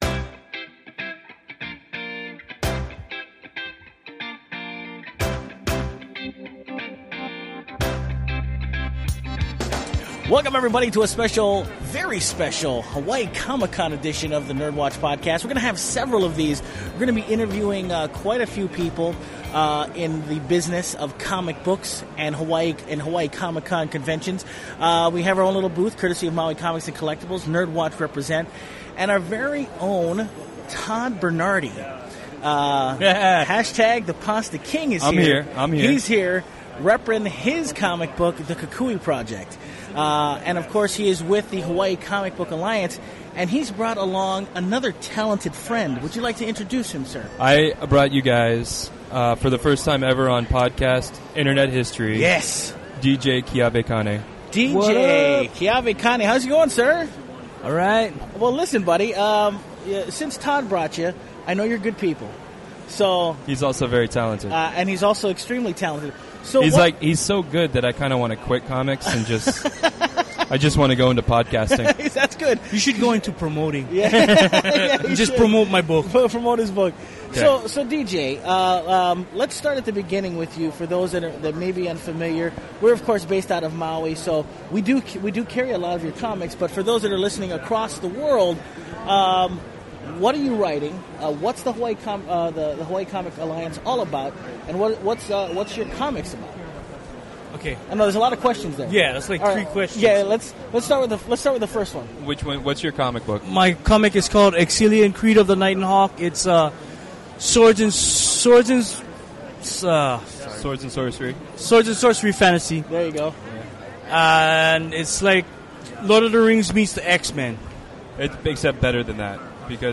NERDWatch Interviews
In our first of a series of Amazing Hawaii Comic Con interviews